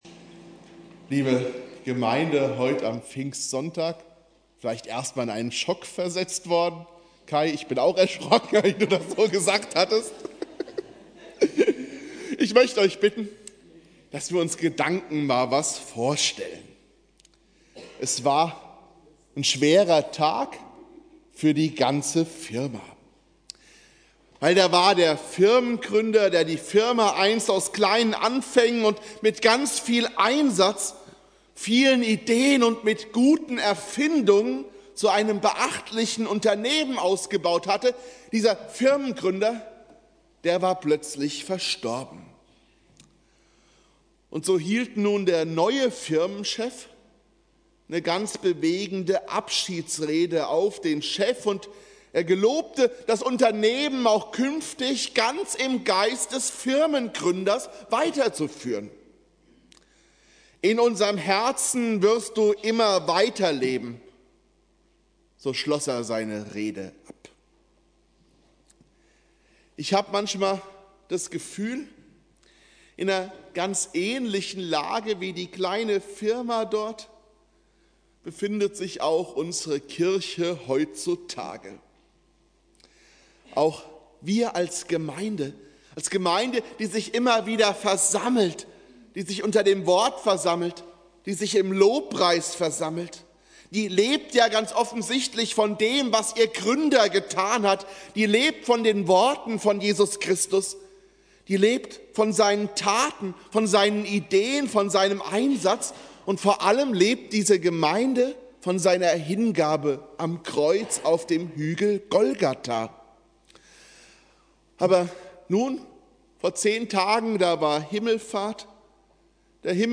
Predigt
Pfingstsonntag